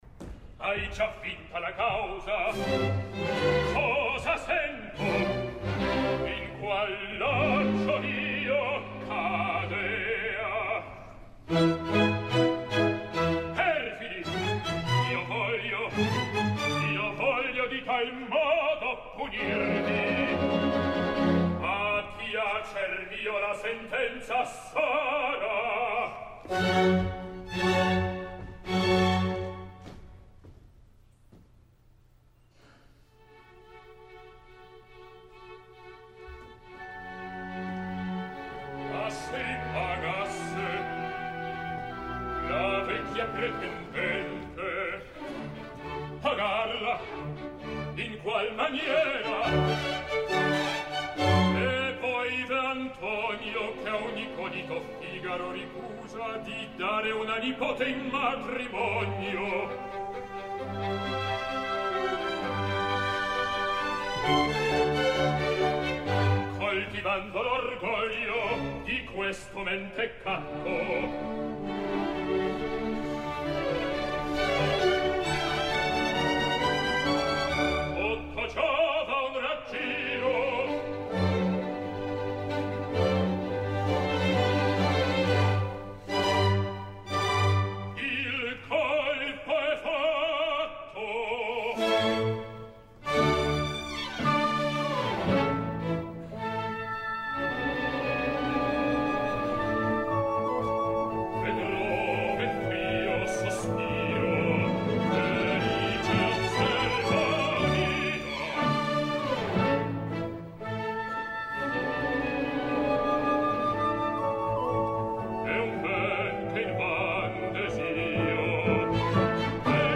Il Conte d’Almaviva…………Mariusz Kwiecien
Lyric Opera of Chicago, 28 de febrer de 2010
Bona veu, interpret notable, expressivitat en els recitatius i coloratura suficient, amb una dicció clara que ens permet entendre tot el que diu.